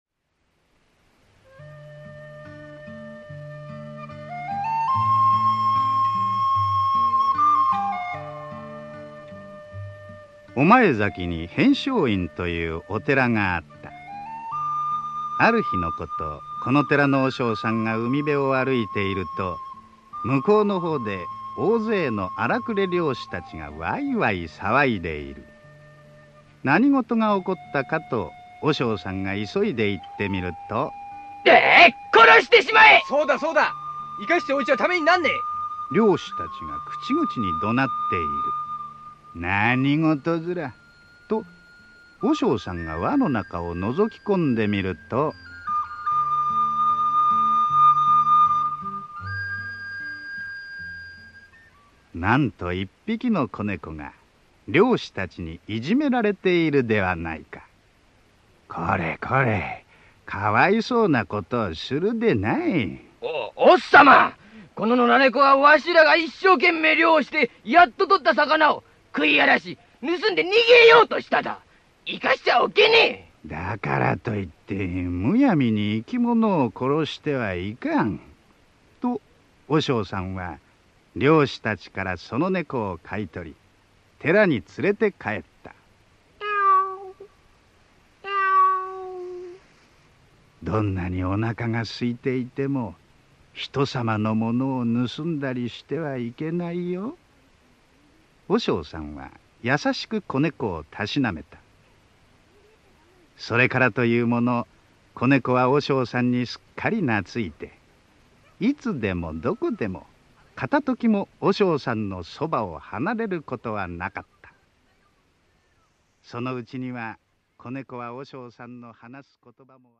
[オーディオブック] ねこずか